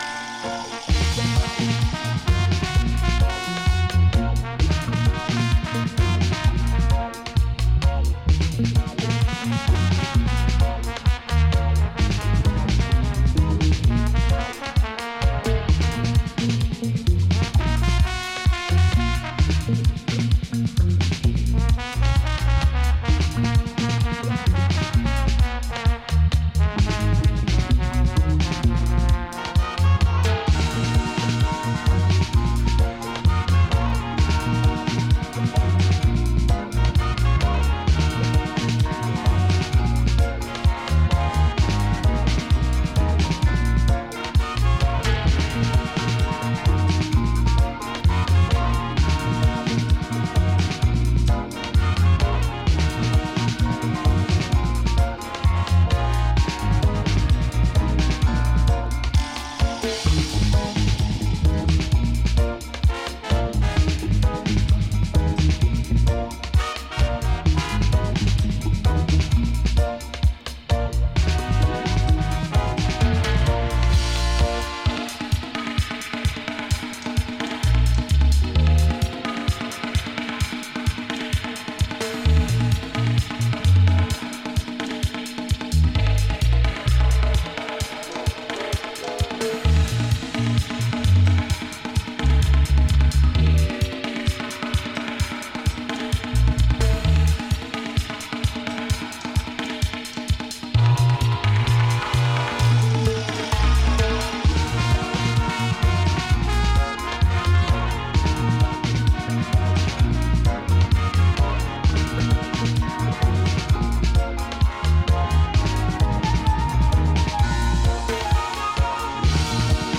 Reggae / Dub